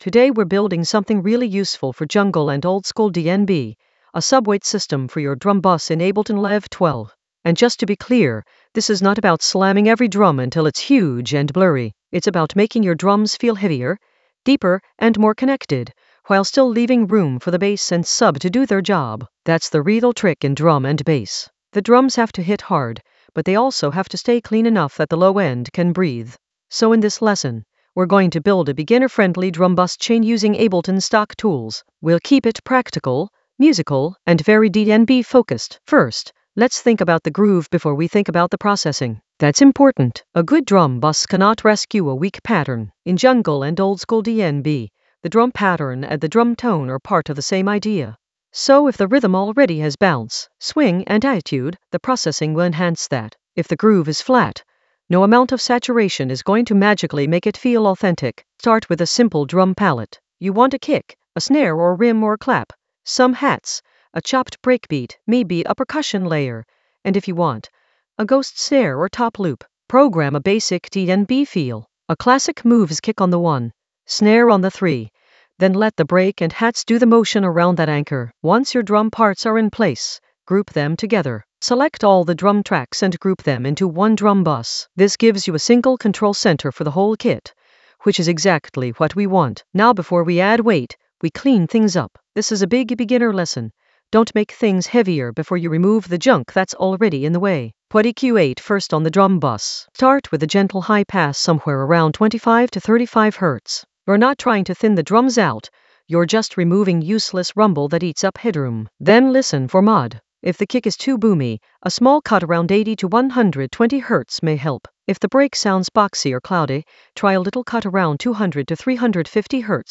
An AI-generated beginner Ableton lesson focused on Subweight system: drum bus compose in Ableton Live 12 for jungle oldskool DnB vibes in the Composition area of drum and bass production.
Narrated lesson audio
The voice track includes the tutorial plus extra teacher commentary.